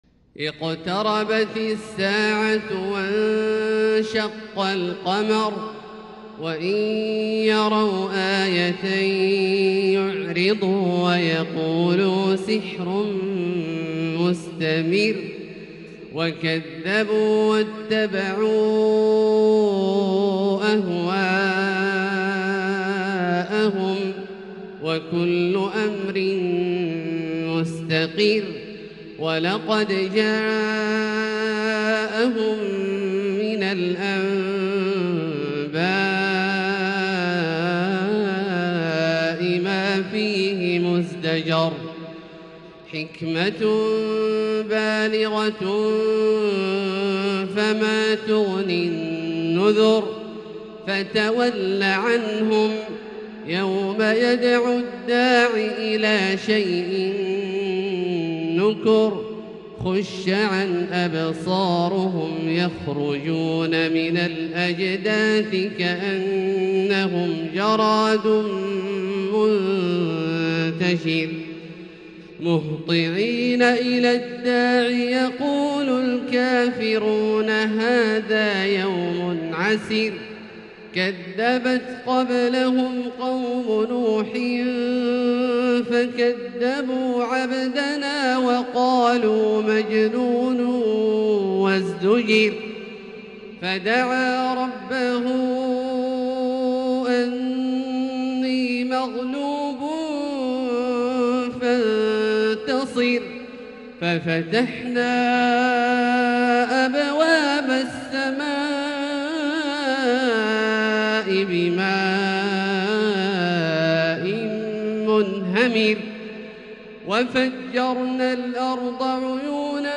تلاوة بديعة لـ سورة القمر كاملة للشيخ د. عبدالله الجهني من المسجد الحرام | Surat Al-Qamar > تصوير مرئي للسور الكاملة من المسجد الحرام 🕋 > المزيد - تلاوات عبدالله الجهني